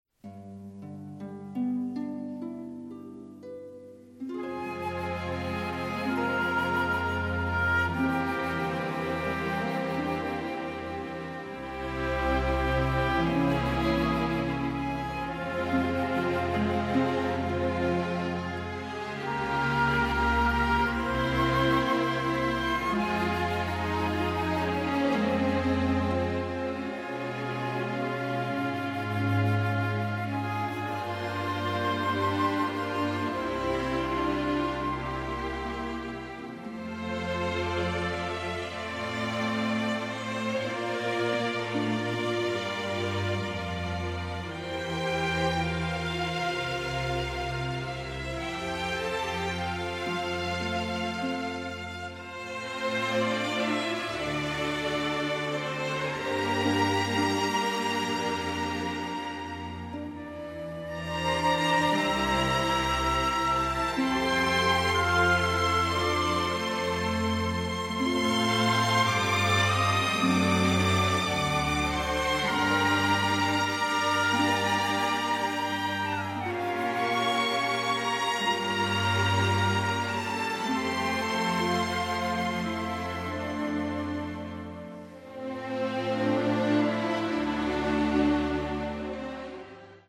the score is operatic in its power